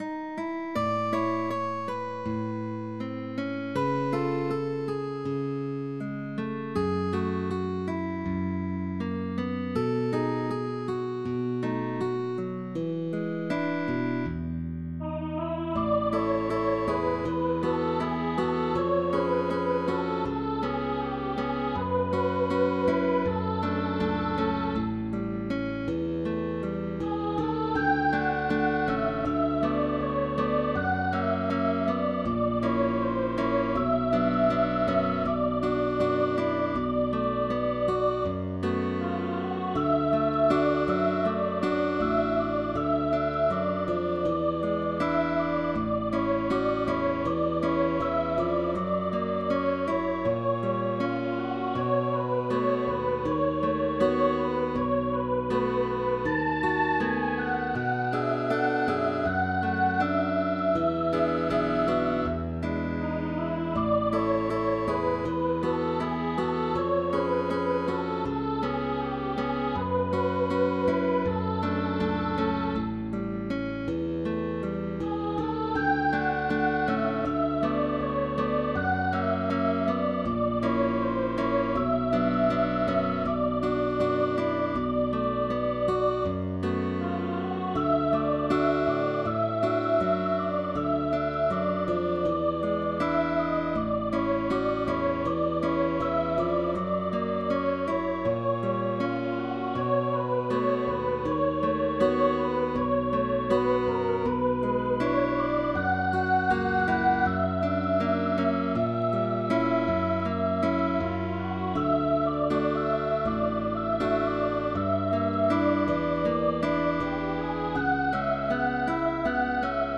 VOZ y GUITARRA